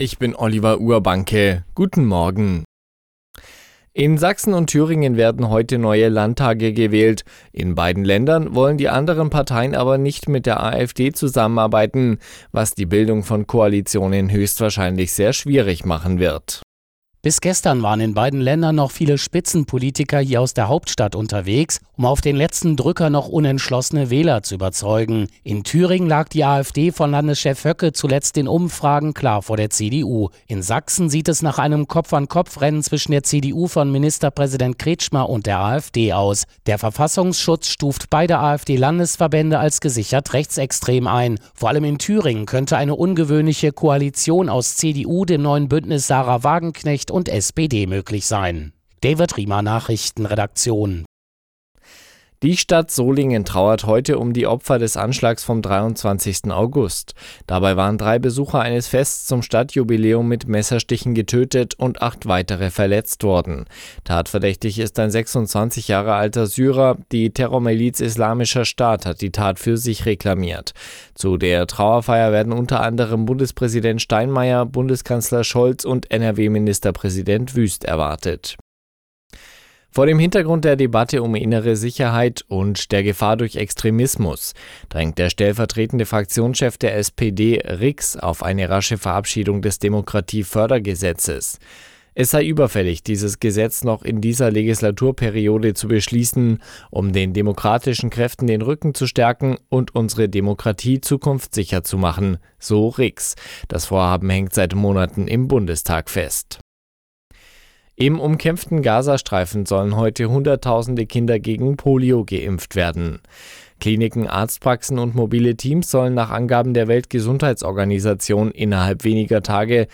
Die Arabella Nachrichten vom Sonntag, 1.09.2024 um 05:59 Uhr - 01.09.2024